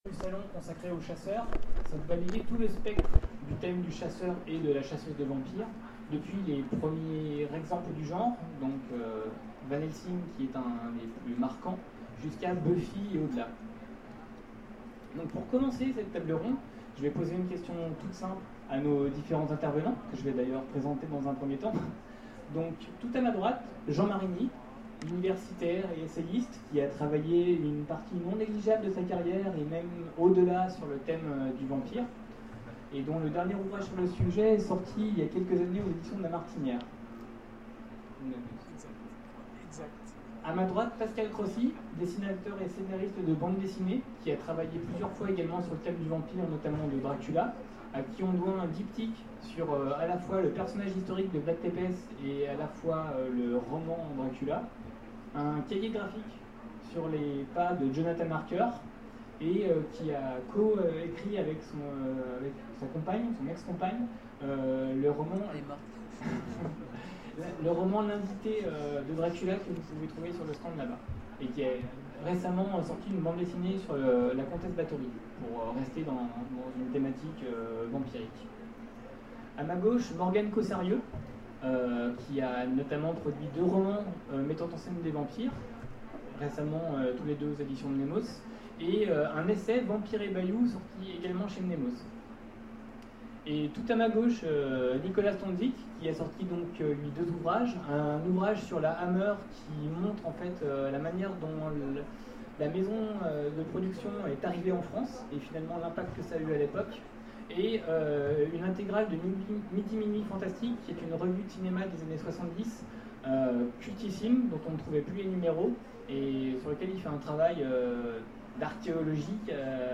Salon du vampire 2014 : Conférence Dracula contre Van Helsing, face à face originel